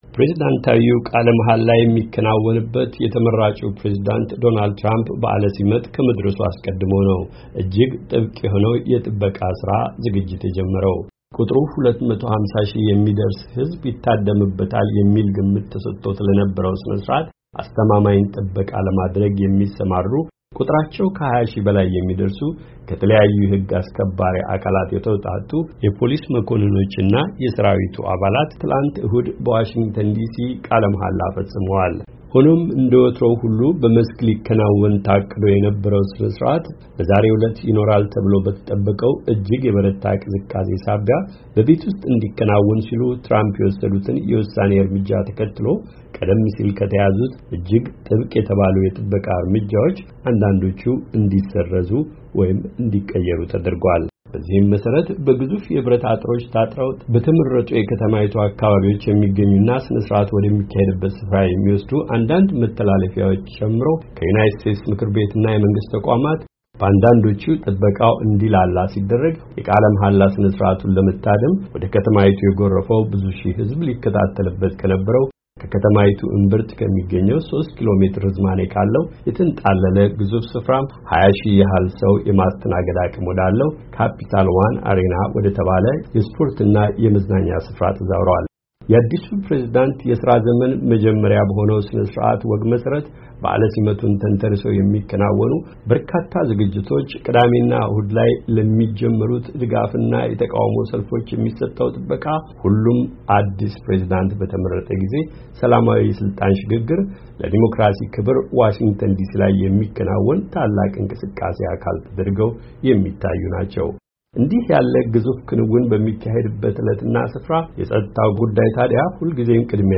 የጥበቃውን ይዘት እና የተደረጉትን ለውጦች አስመልክቶ ያጠናቀረውን ዘገባ እንደሚከተለው ያቀርበዋል።